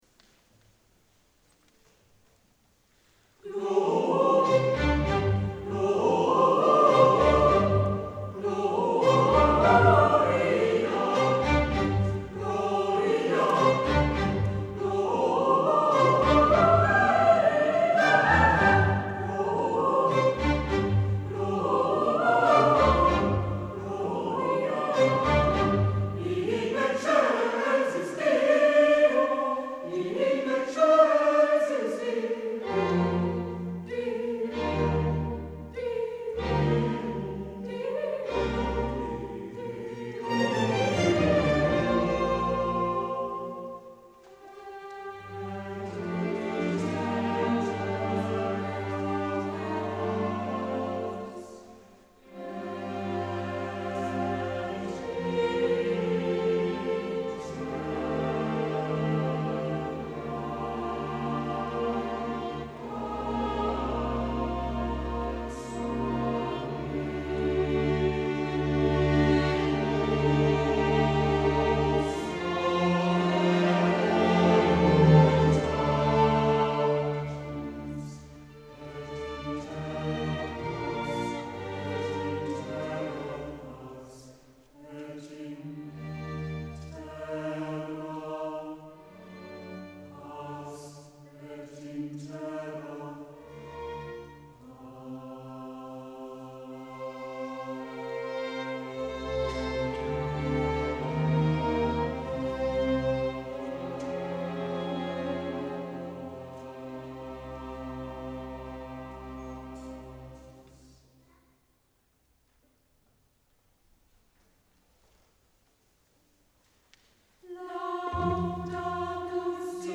• Concerto Elmshorn und Cantus Hamburg
Probe des Zusammenspiels
Gemeinsame Probe des Ensembles Concerto Elmshorn und Catus Hamburg vor dem Konzert in der Ansgarkirche Othmarschen